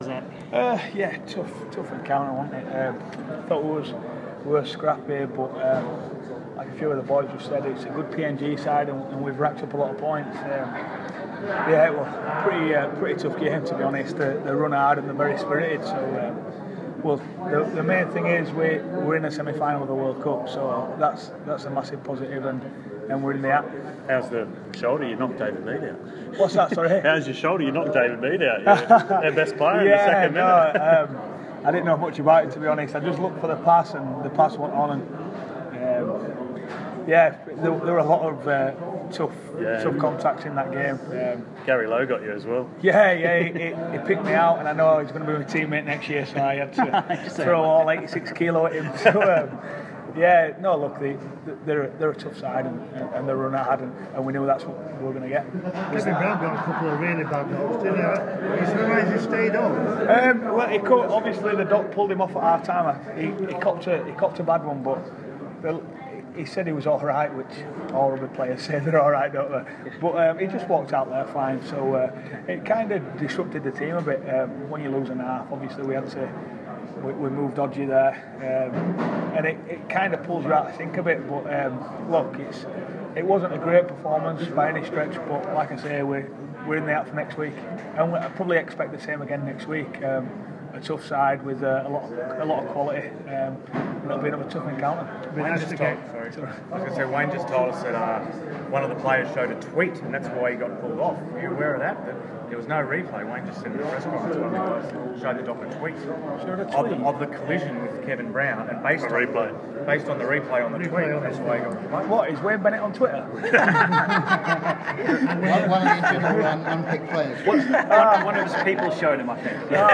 The Castleford man spoke after helping guide England into the semi-finals of the World Cup